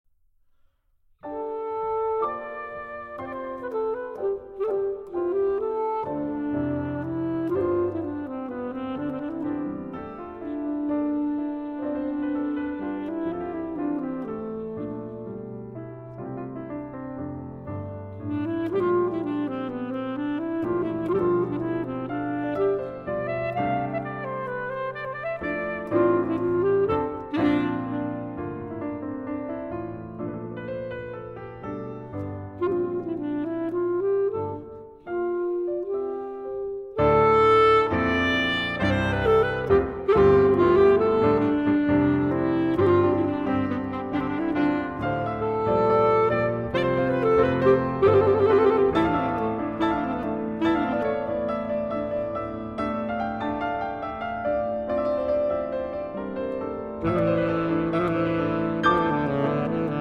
Sonata for Alto Saxophone and Piano